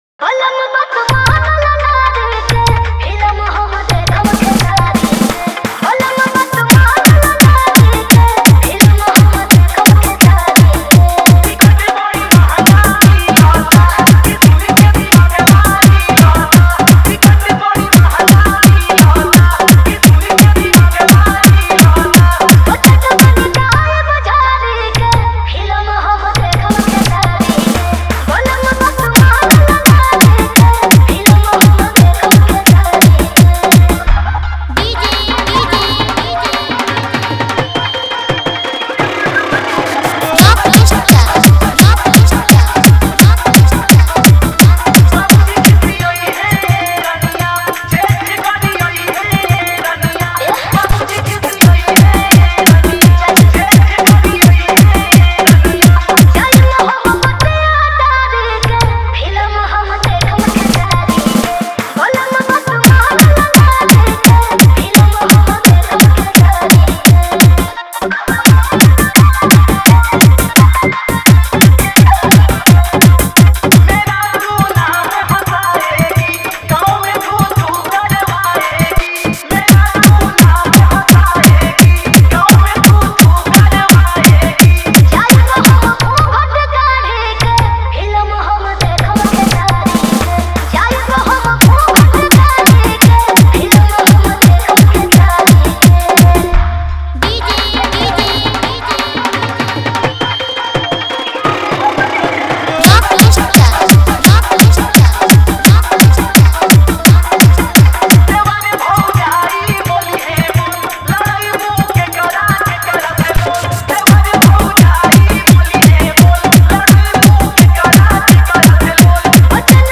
Category:  Bhojpuri Dj Remix